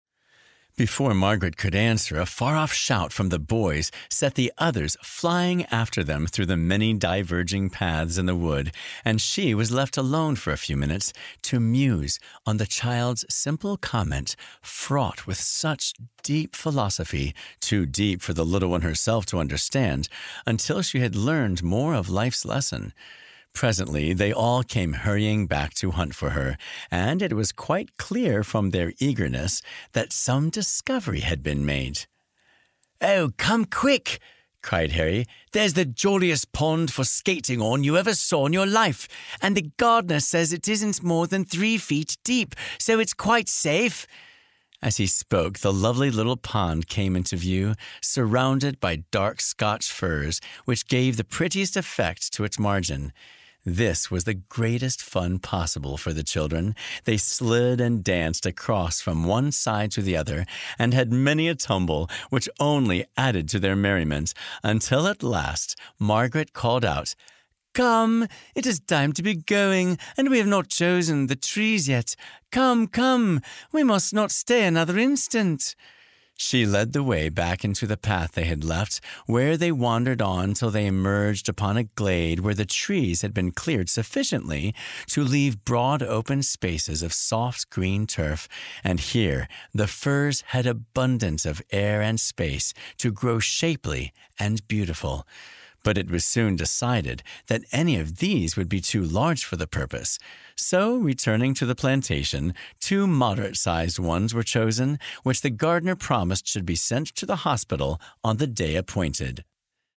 City-Sparrows-Audiobook-Sample.mp3